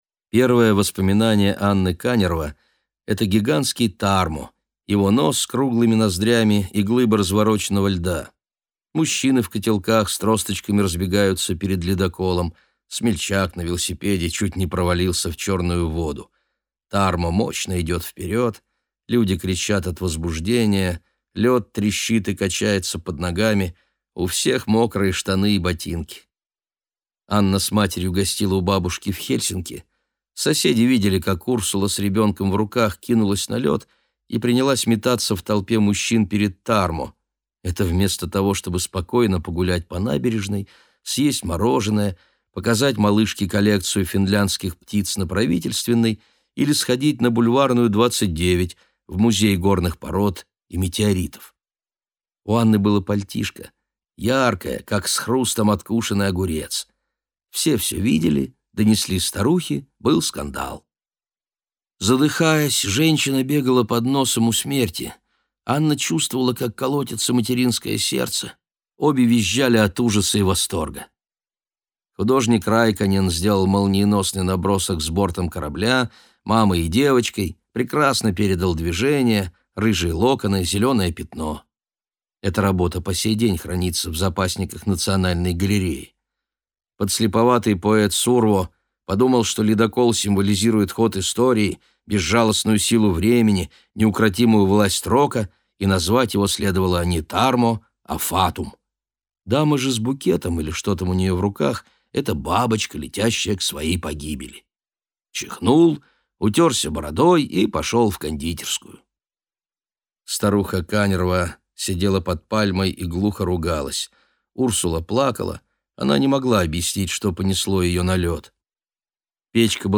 Аудиокнига Хроника Горбатого | Библиотека аудиокниг